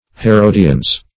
Meaning of herodiones. herodiones synonyms, pronunciation, spelling and more from Free Dictionary.
Search Result for " herodiones" : The Collaborative International Dictionary of English v.0.48: Herodiones \He*ro`di*o"nes\ (h[-e]*r[=o]`d[i^]*[=o]"n[=e]z), n. pl.